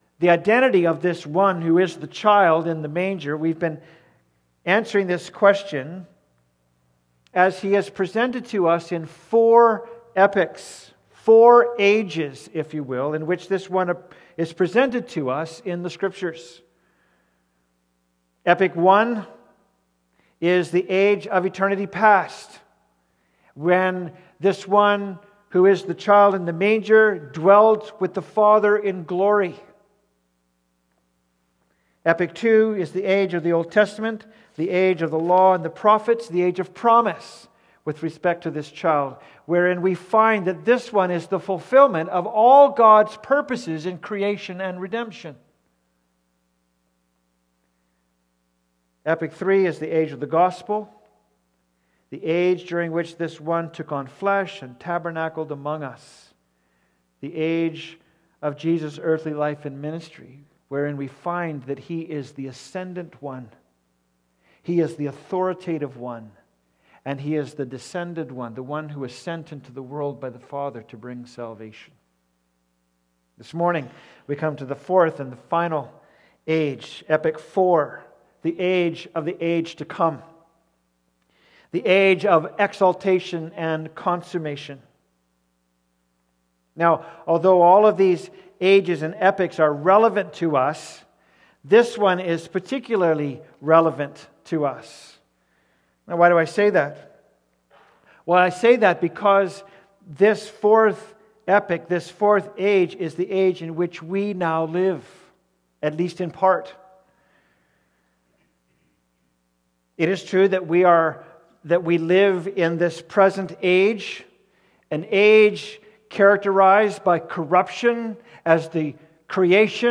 Service Type: Sunday Service Preacher